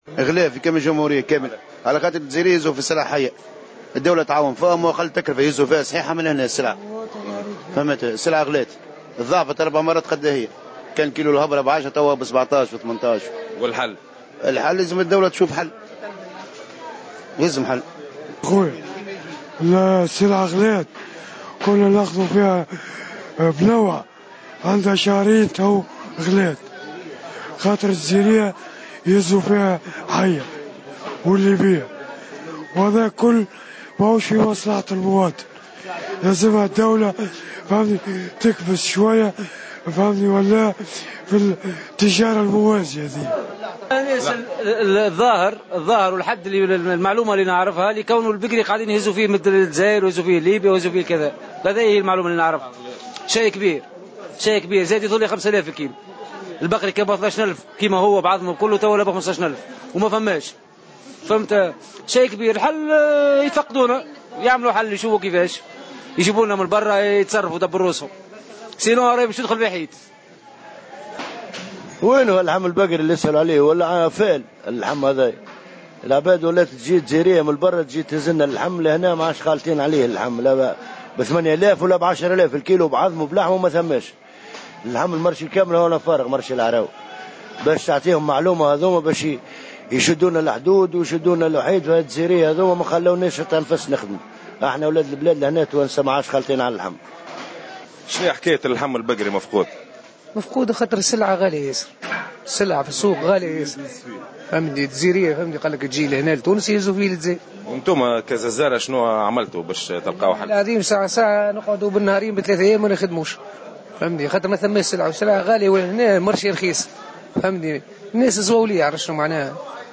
عبر عدد من القصابين بسوق "العراوة" بسوسة عن استيائهم الشديد من الارتفاع المشط لأسعار اللحم البقري في الأيام الأخير.